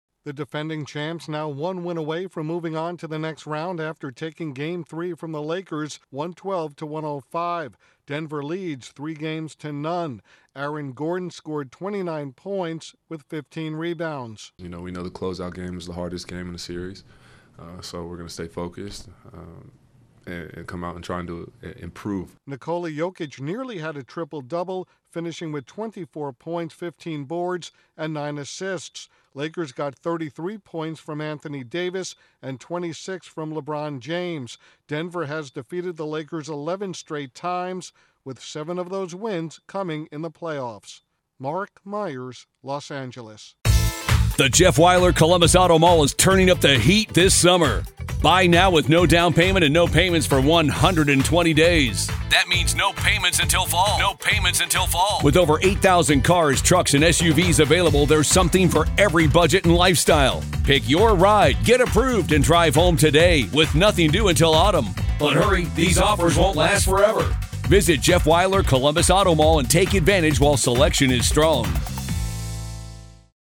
reports from Los Angeles